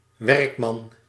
Ääntäminen
France: IPA: [ma.nœvʁ] Paris